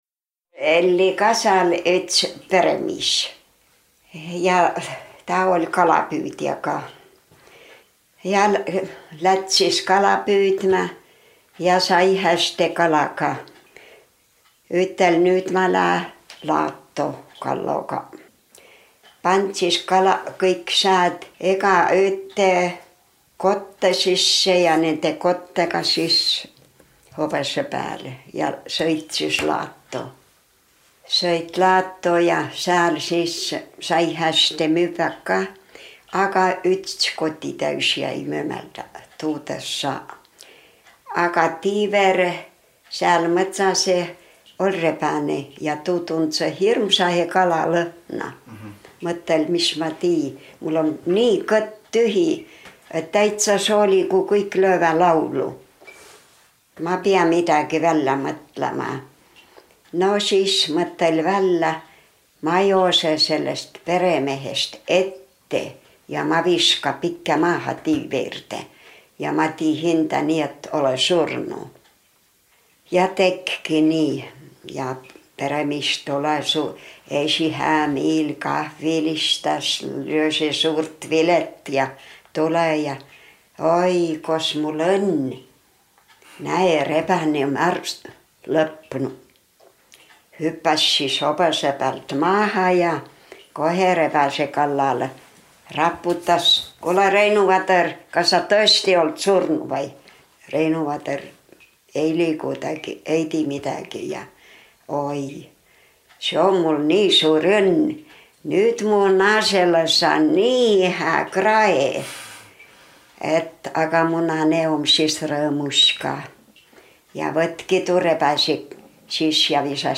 Saa pruuki ütehkuuh “Seto aabidsaga” (2011) J-tähe opmisõl vai eräle. Peri plaadi päält “Juttõ ja laulõ seto aabitsa mano”.